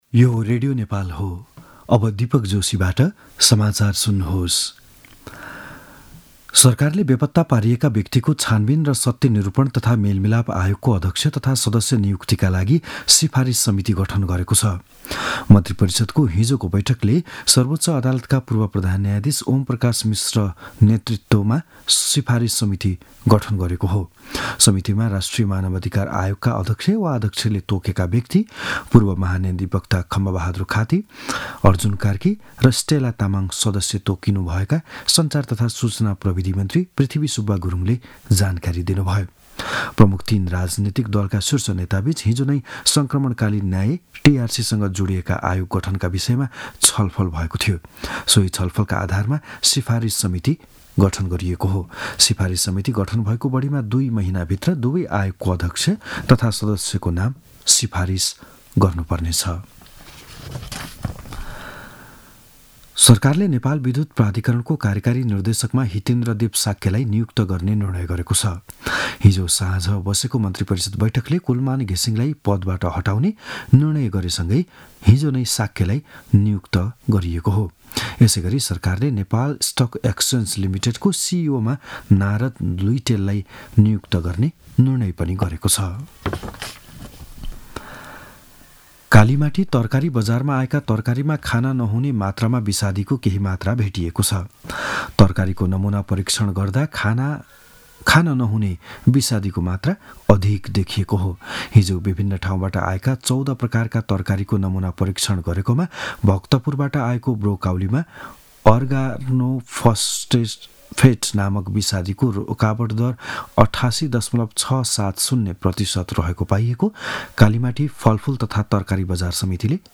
बिहान ११ बजेको नेपाली समाचार : १२ चैत , २०८१
11-am-news-1-9.mp3